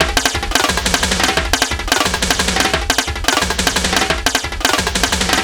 ___TEK TOM 2.wav